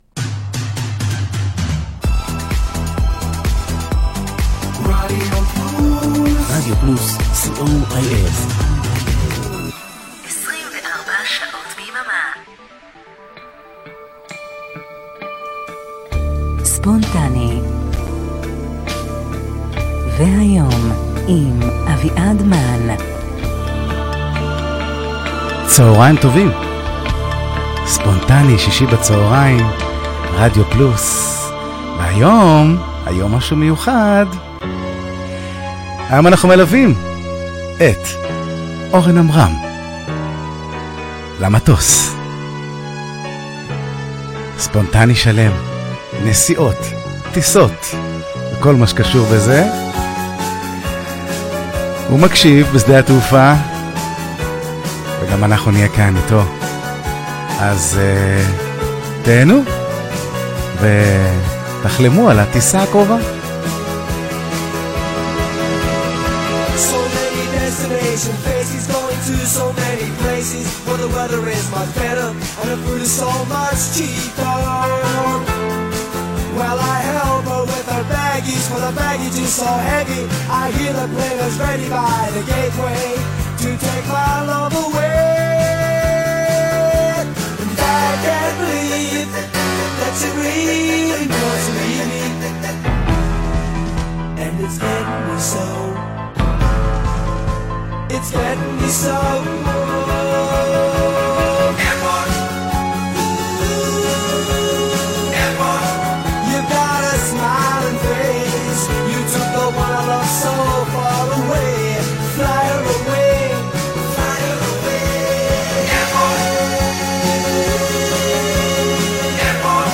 אז החלטנו ללוות אותו ברצף של שירי טיסות ונסיעות.